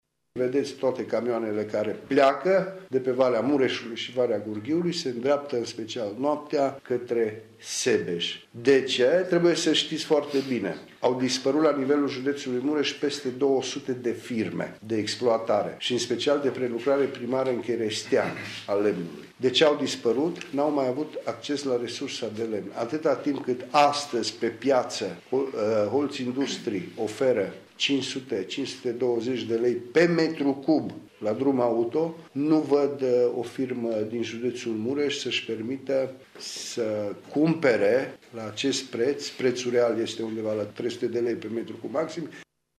Declaraţia aparţine deputatului PNL Cristian Chirteş şi a fost făcută în contextul discuţiilor legate de modificarea Codului Silvic.